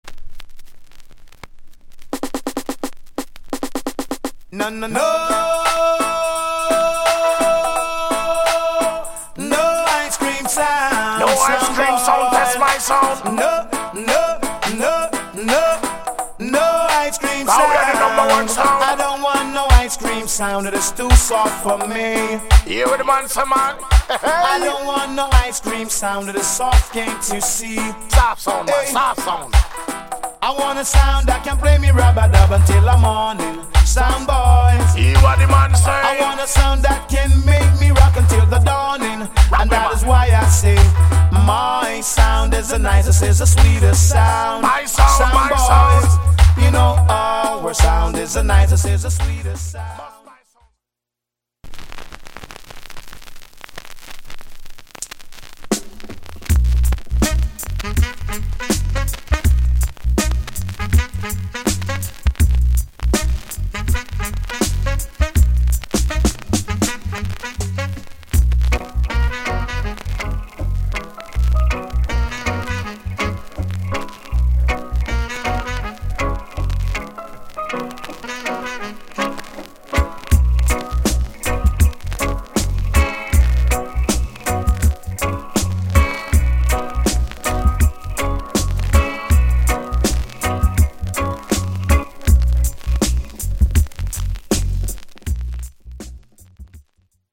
ダブ・プレートでお馴染みのBig Sound Tune!